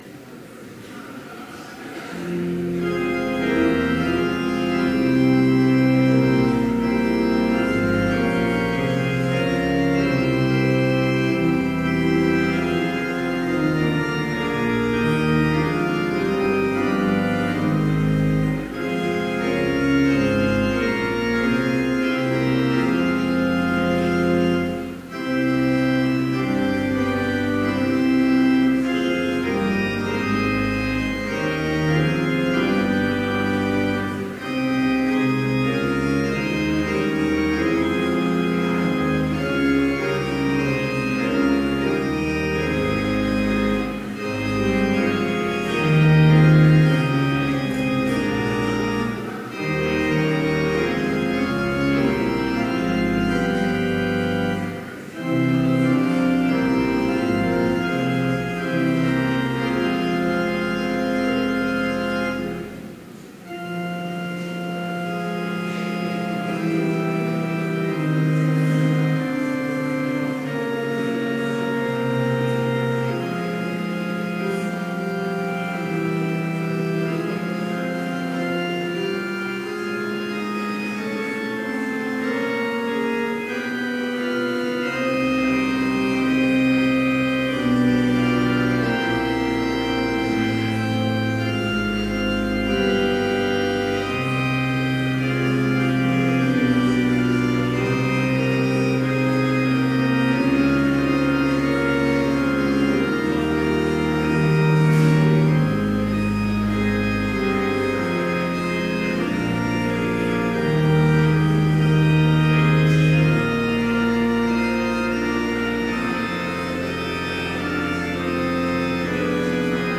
Complete service audio for Chapel - October 27, 2017